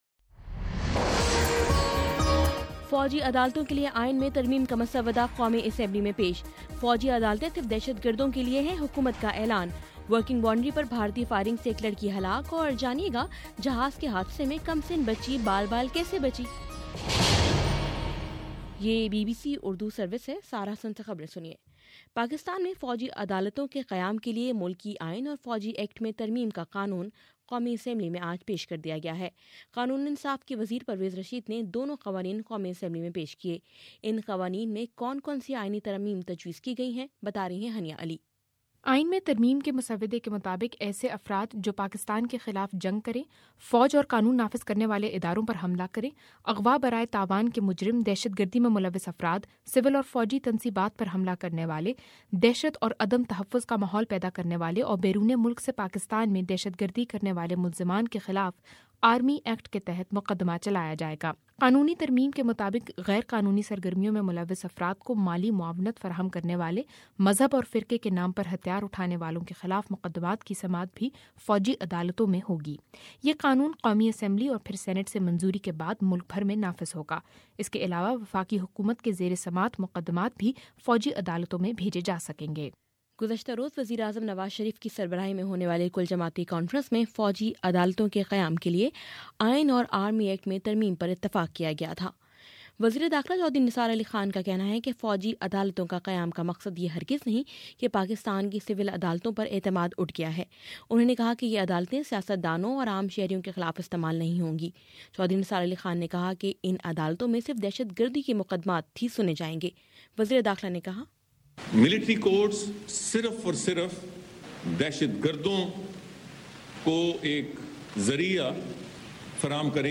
جنوری 03: شام سات بجے کا نیوز بُلیٹن